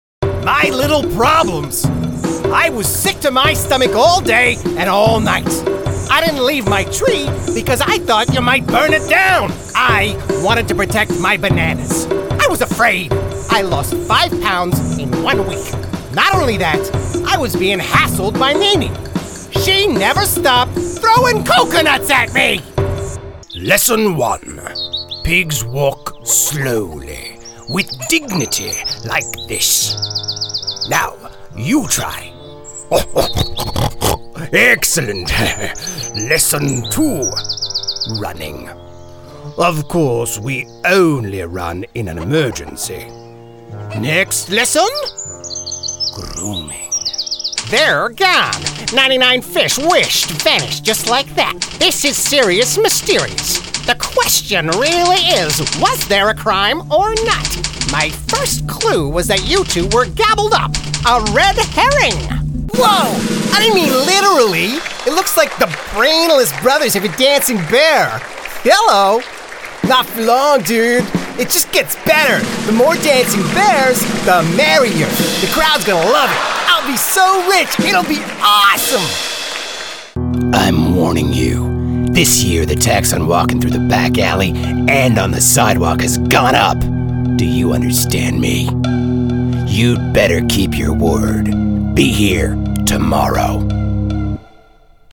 Animation - EN